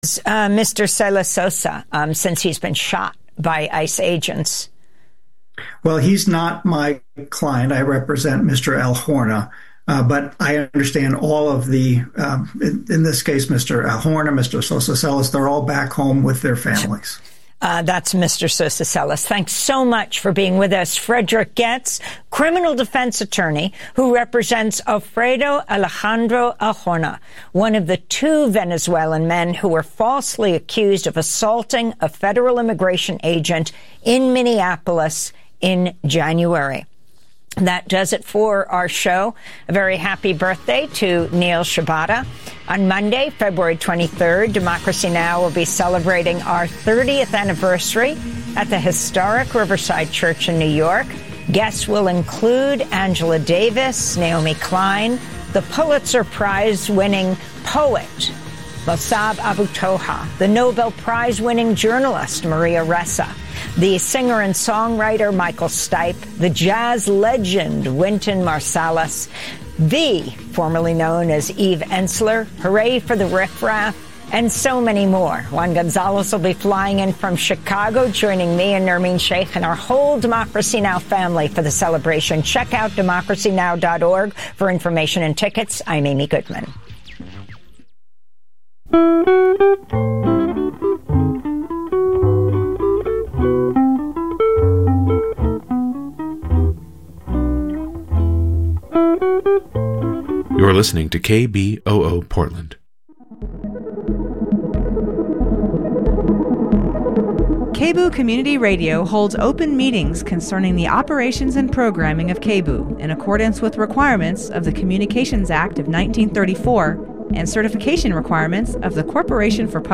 Hosted by: KBOO News Team